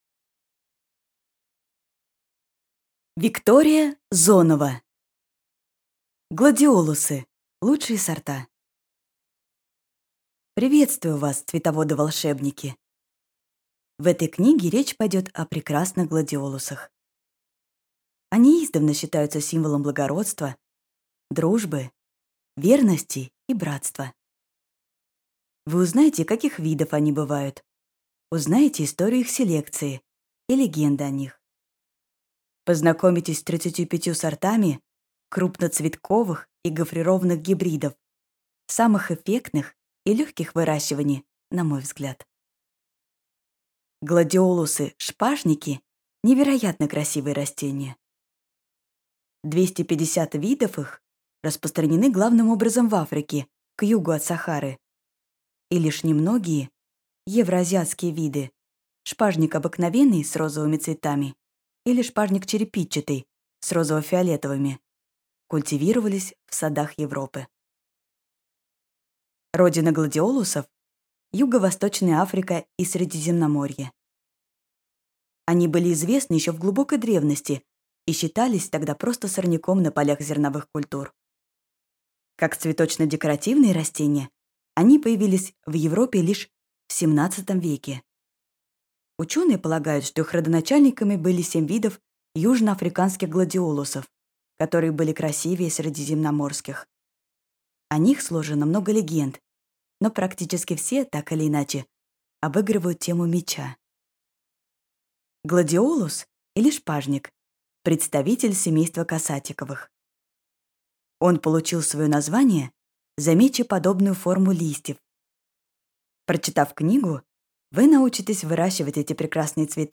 Аудиокнига Гладиолусы. Лучшие сорта | Библиотека аудиокниг